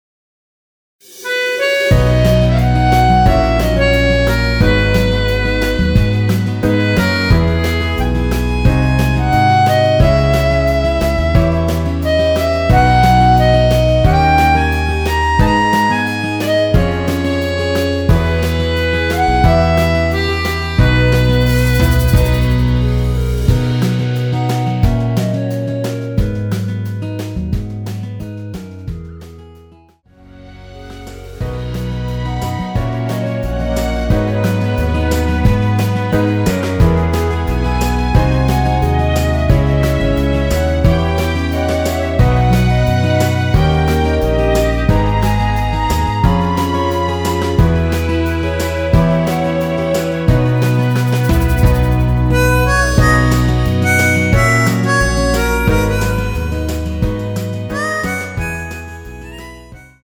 원키에서(+1)올린 멜로디 포함된 MR입니다.(미리듣기 확인)
앞부분30초, 뒷부분30초씩 편집해서 올려 드리고 있습니다.